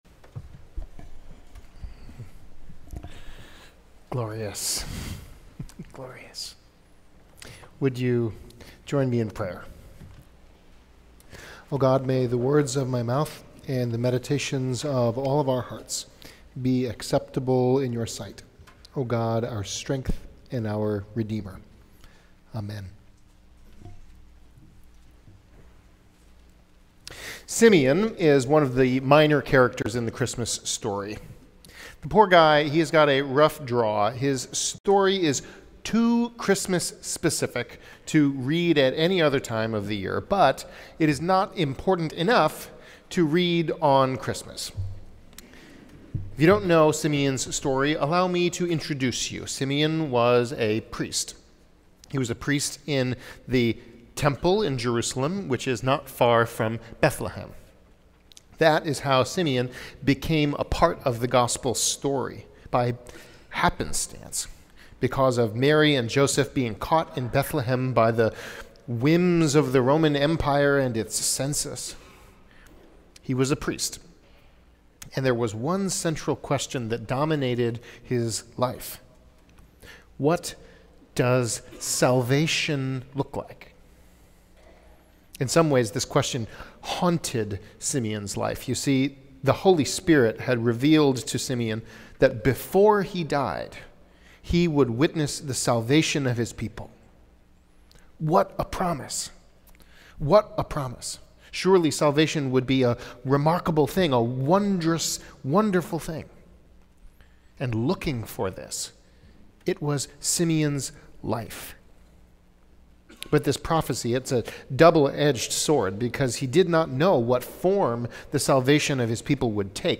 Christmas Eve Candlelight Service of Lessons & Carols
sermon audio_42.mp3